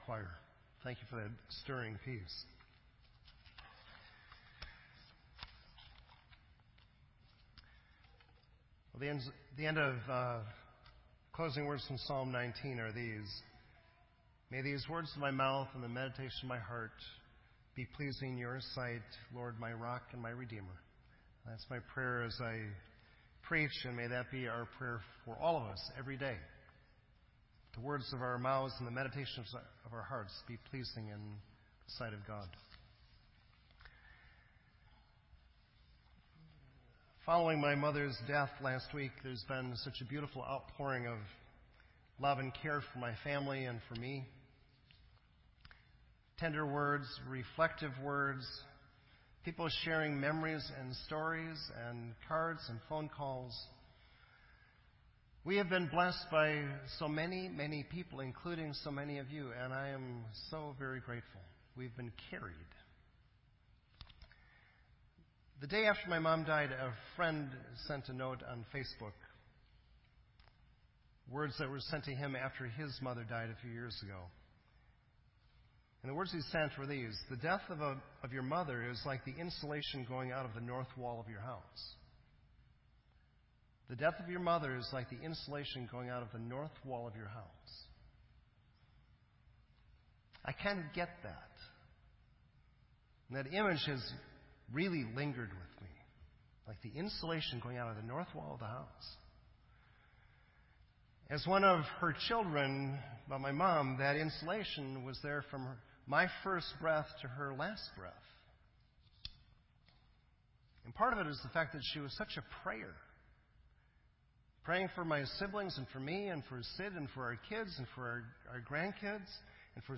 This entry was posted in Sermon Audio on October 15